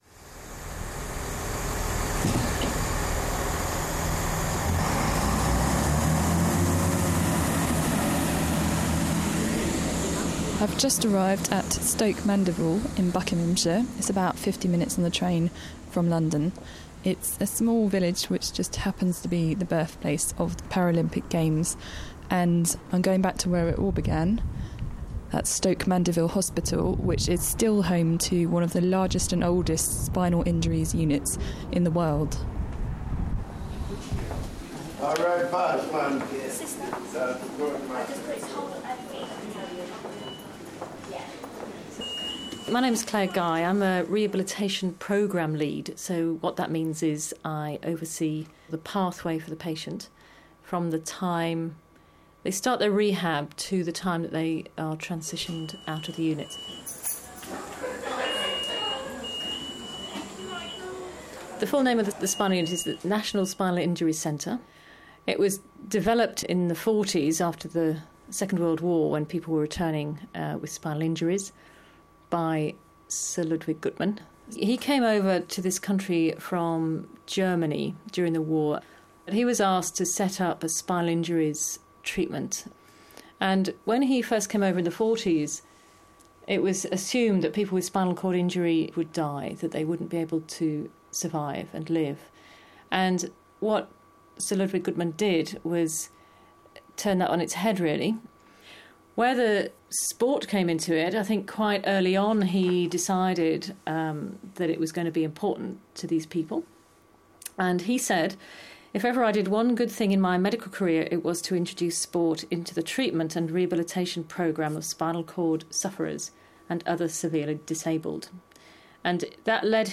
Audio feature on the origins of the Paralympics in Stoke Mandeville. Recorded for Round 1, the Roundhouse's London 2012-themed radio show.